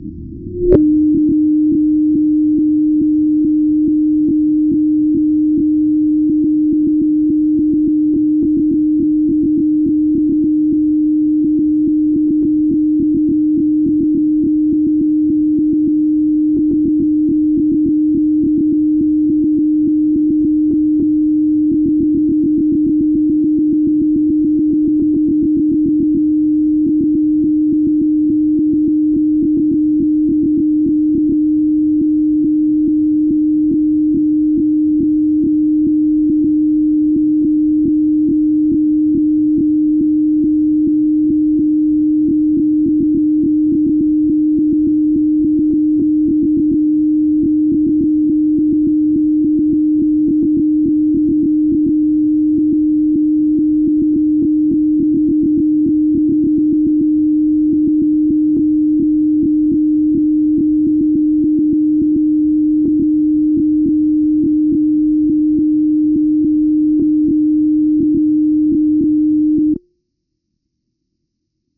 When transmitting, the datagrams are sent in morse code at very high speed, in order to reduce the chance of interception and location by means of Radio Direction Finding ( RDF ).
R394KM_message_slow.mp3